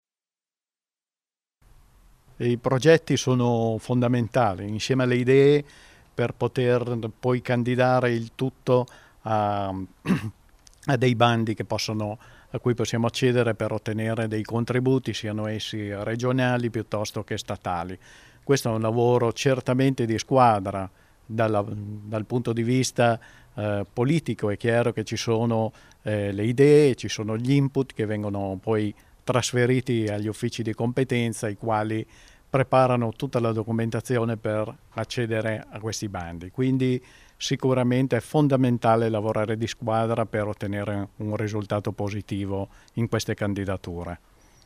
Vanni Deluigi, Assessore – Bilancio e tributi